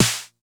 Index of /90_sSampleCDs/Club-50 - Foundations Roland/KIT_xTR909 Kits/KIT_xTR909 3
SNR XC.SNR00.wav